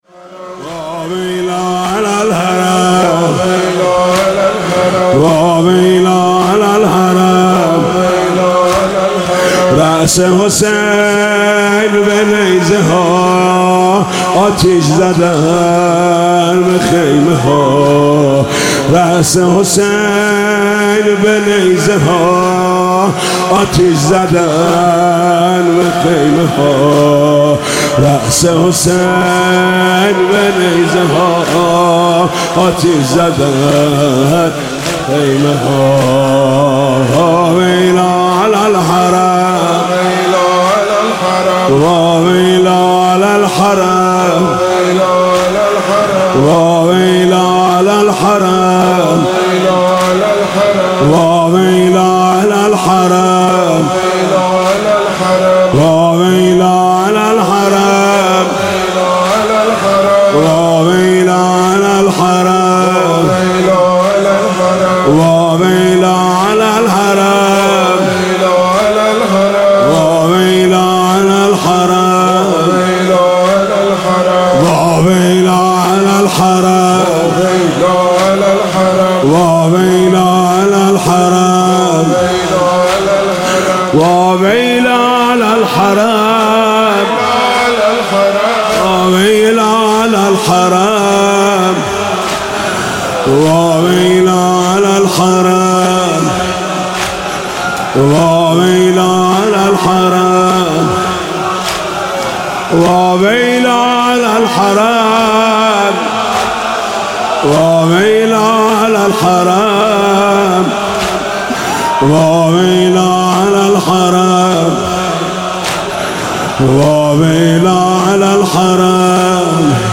شام غریبان